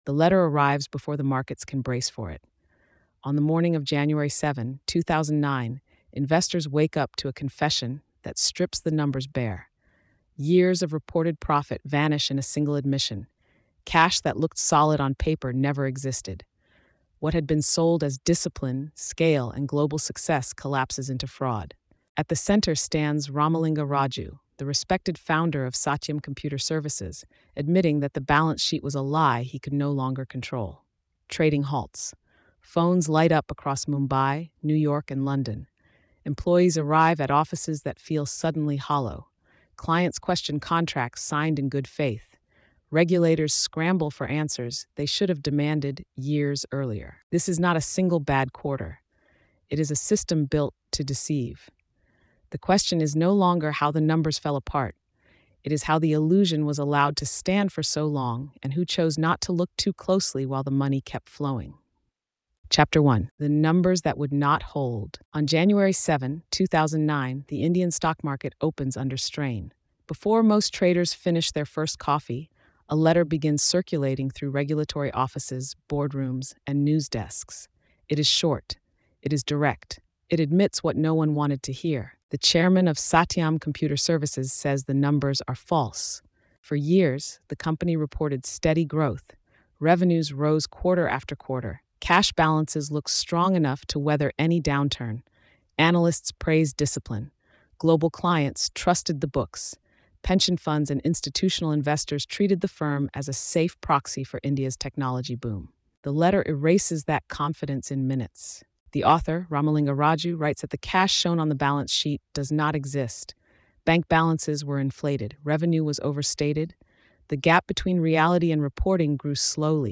India’s Enron: The Satyam Computer Services Scandal is a gritty investigative account of one of the largest corporate frauds in global business history. Told with journalistic precision and rising tension, the series traces how Satyam Computer Services presented itself as a pillar of India’s technology boom while hiding years of fabricated revenue and nonexistent cash.